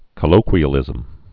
(kə-lōkwē-ə-lĭzəm)